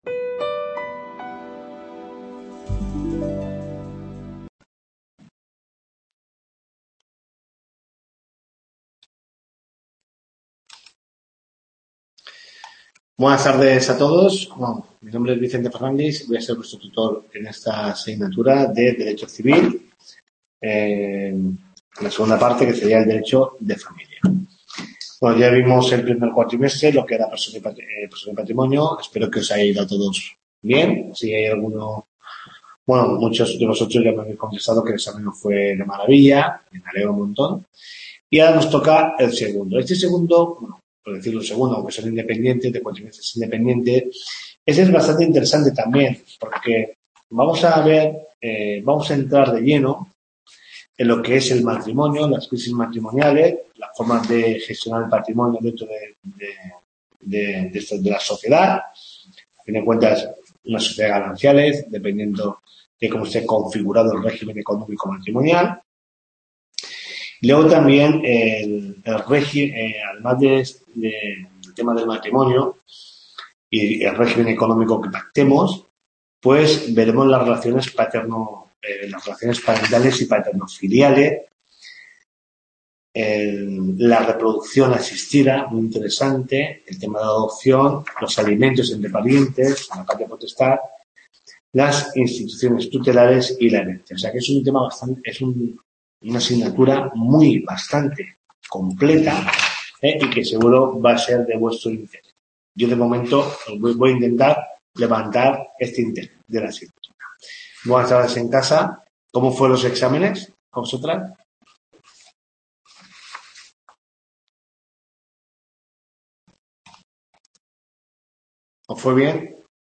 TUTORIA 1